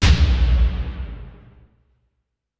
Big Drum Hit 25.wav